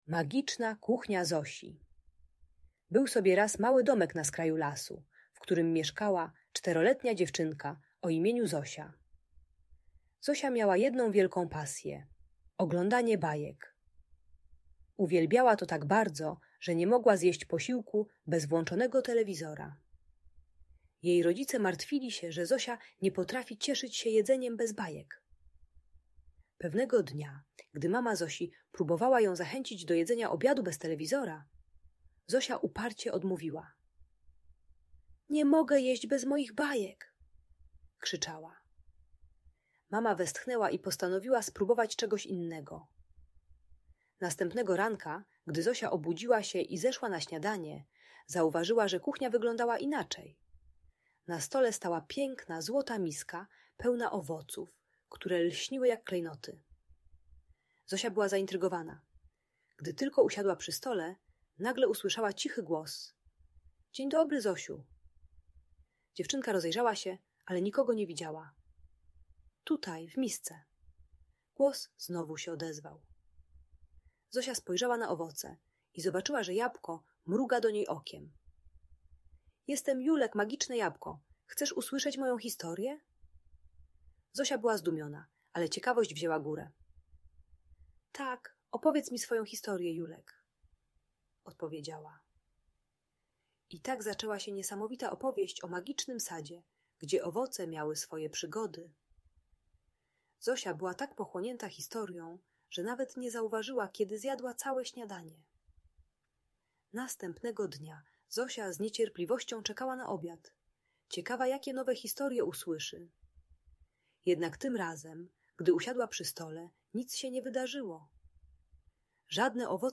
Magiczna Kuchnia Zosi - Bajki Elektronika | Audiobajka